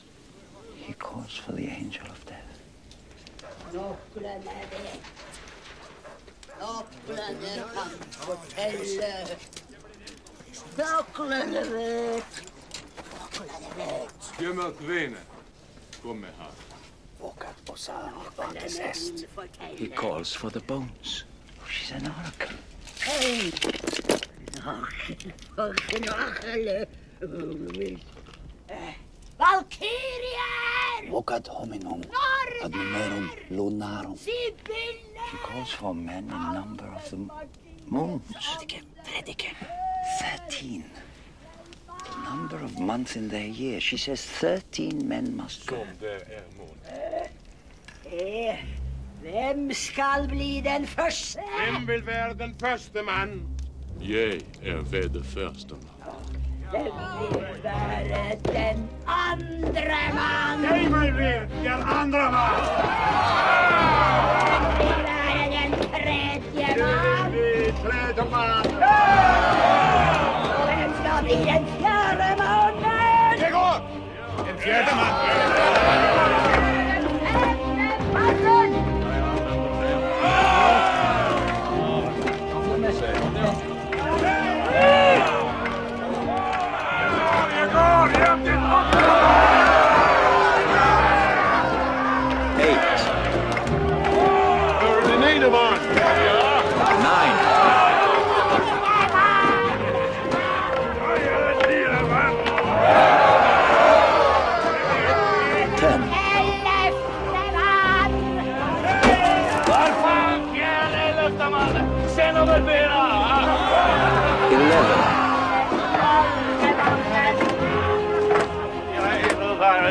In these tent scenes, there is also a three fold translation going on: the north men's dialect is translated to Latin and then to English.